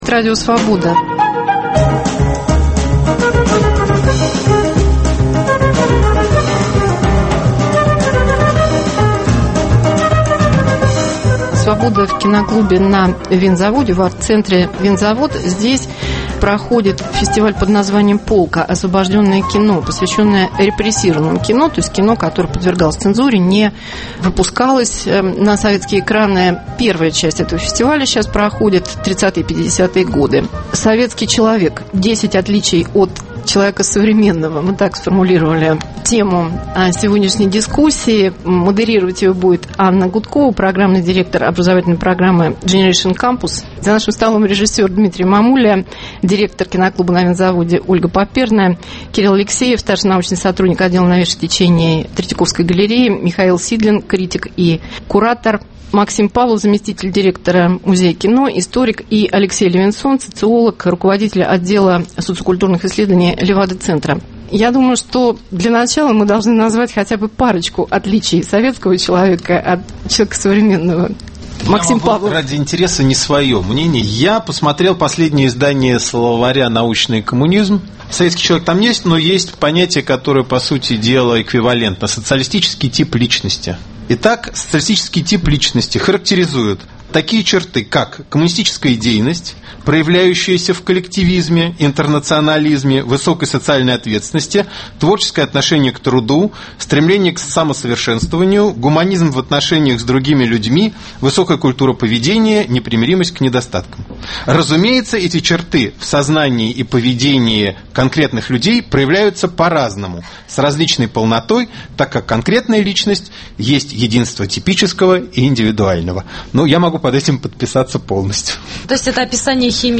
Свобода в Киноклубе в арт-центре "Винзавод" Советский человек: 10 отличий от современного. Моральный кодекс строителя коммунизма и постсоветская мораль.